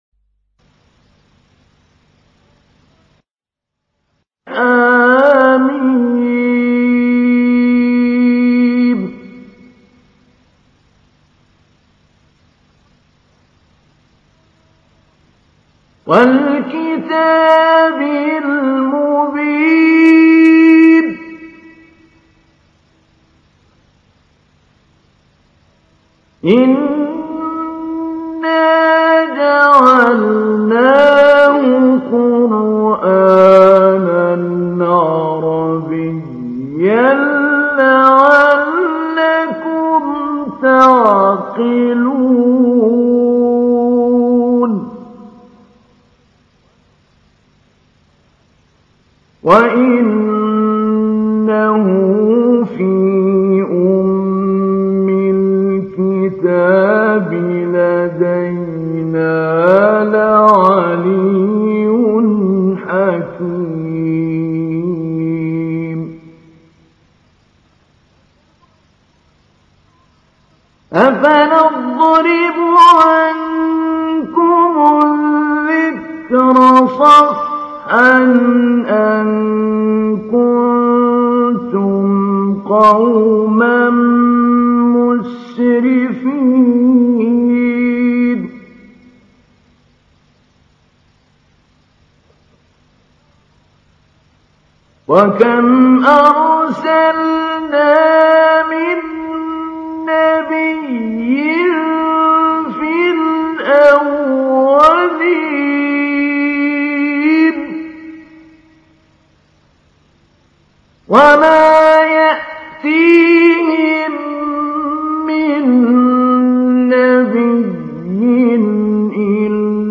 تحميل : 43. سورة الزخرف / القارئ محمود علي البنا / القرآن الكريم / موقع يا حسين